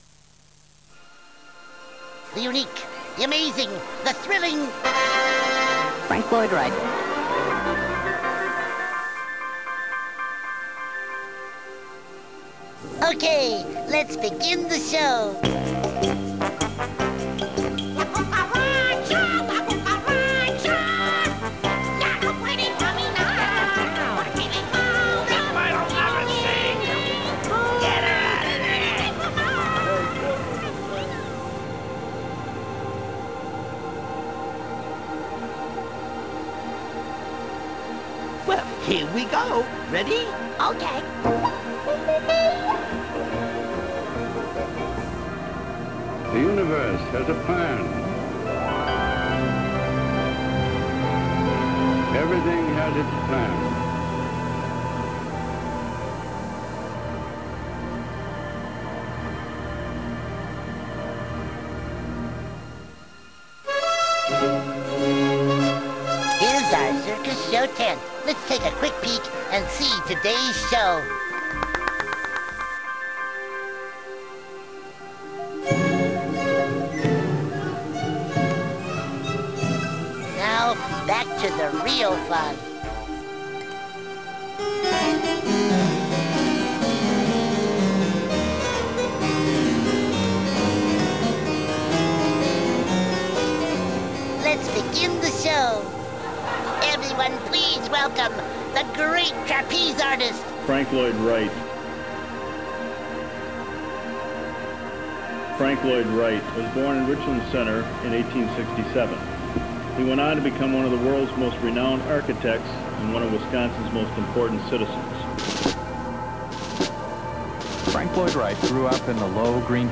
AMBIENT5.mp3